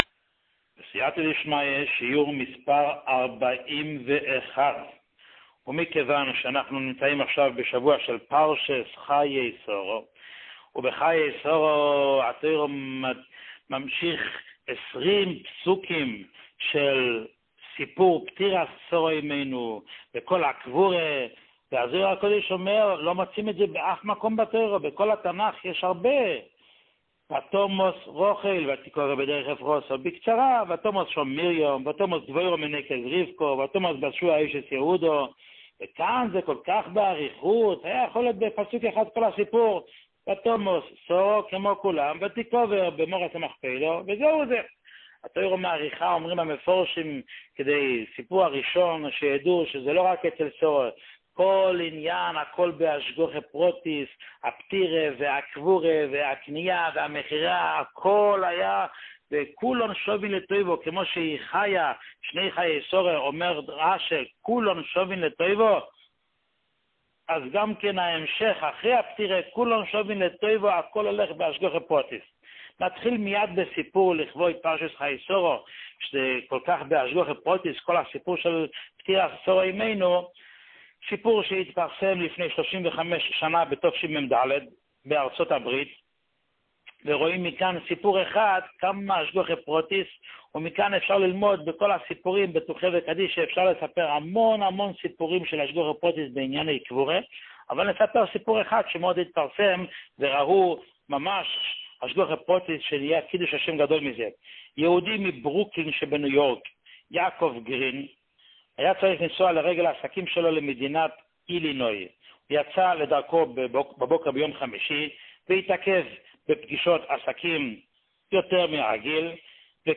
שיעורים מיוחדים
שיעור 41